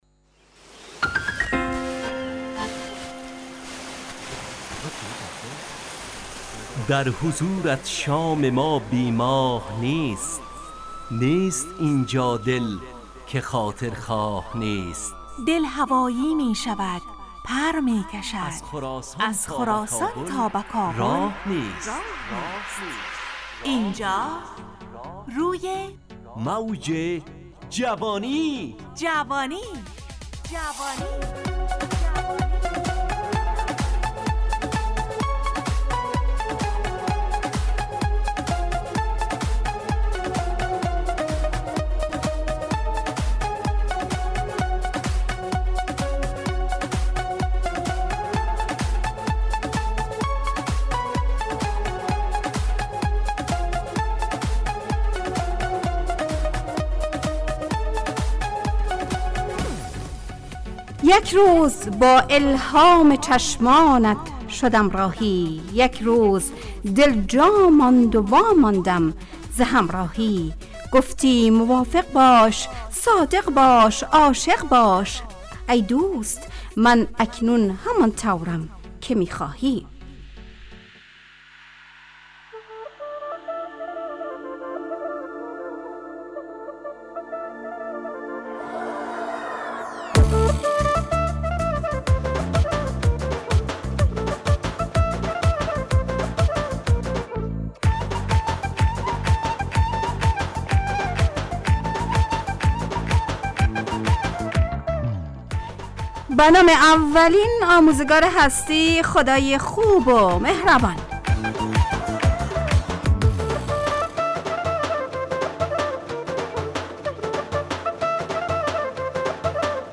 روی موج جوانی، برنامه شادو عصرانه رادیودری.
همراه با ترانه و موسیقی مدت برنامه 70 دقیقه . بحث محوری این هفته (آموزگار) تهیه کننده